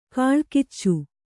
♪ kāḷkiccu